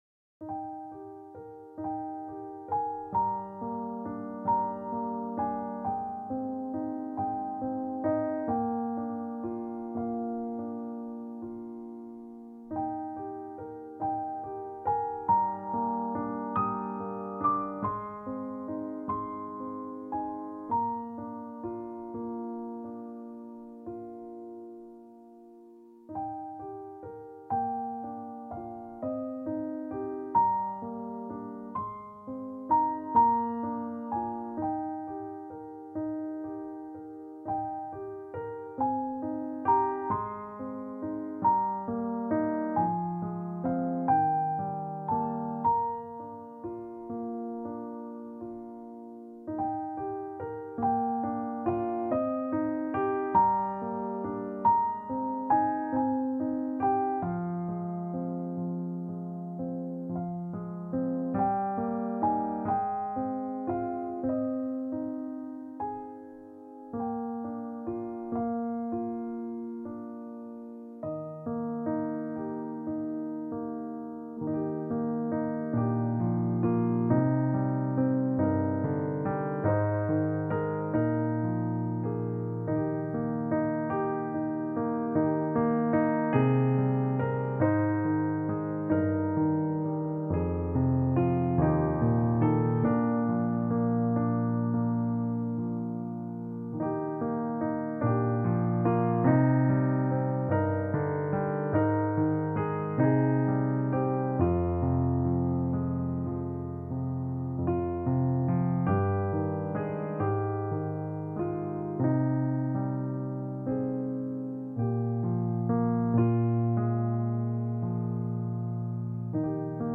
Voicing/Instrumentation: Piano Solo We also have other 62 arrangements of " Abide With Me ".